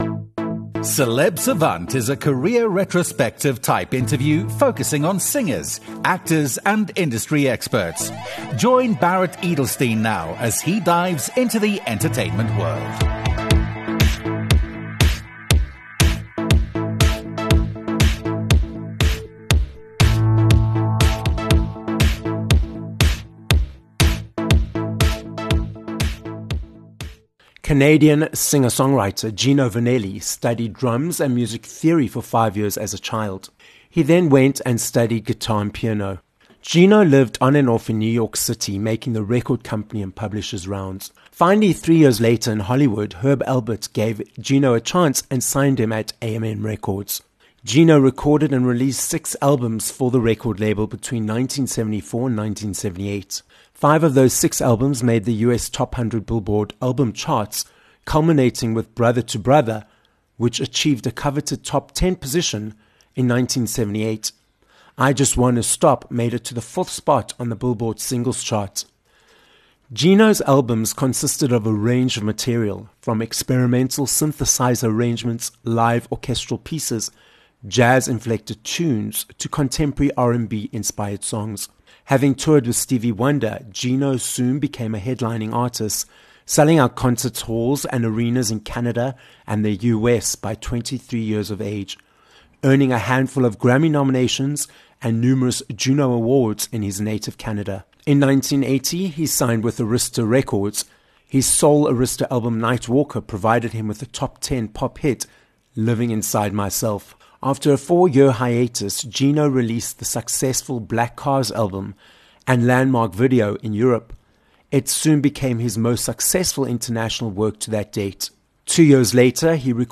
Gino Vannelli - a Canadian singer, songwriter, author, 7x Juno Award winner, and 4× Grammy Award nominee - joins us on this episode of Celeb Savant. Gino tells us what keeps him motivated to create music and perform live, and what inspires his creativity. We also discuss various aspects of the entertainment industry, which he has successfully been part of for multiple decades.